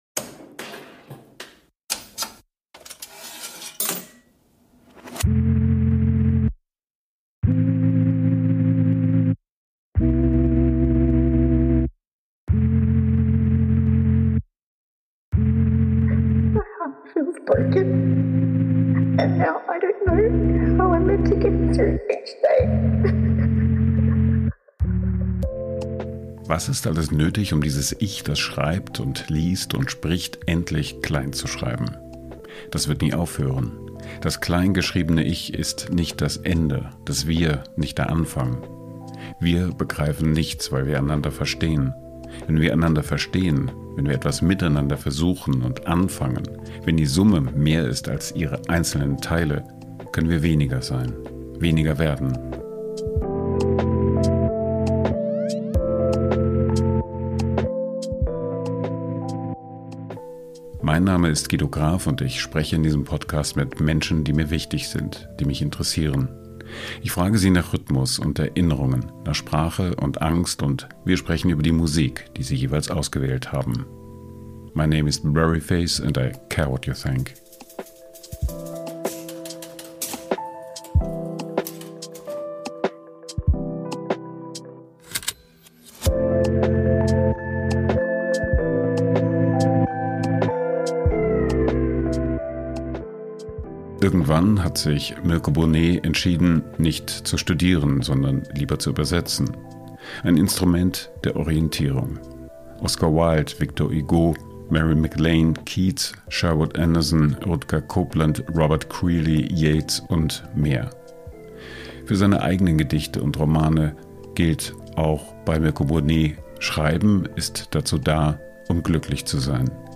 Ich frage sie nach Rhythmus und Erinnerungen, nach Sprache und Angst und wir sprechen über die Musik, die sie jeweils ausgewählt haben.